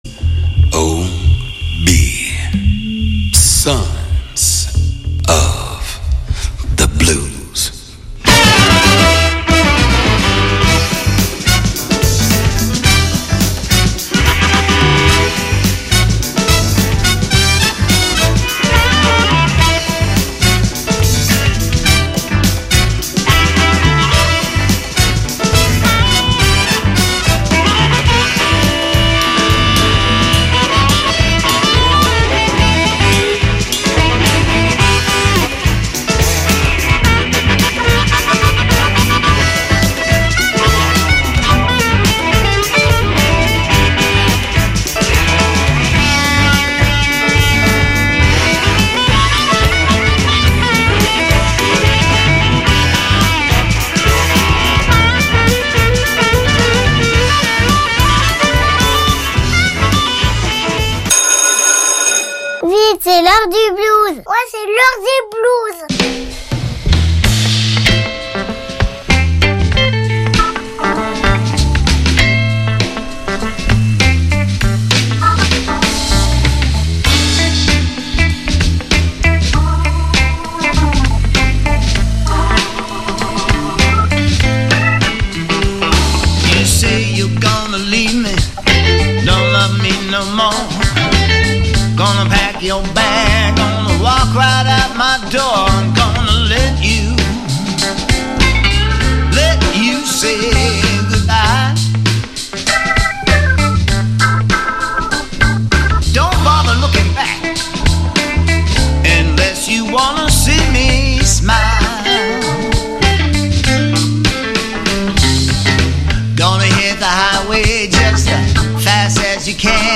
Sons of Blues : musiques blues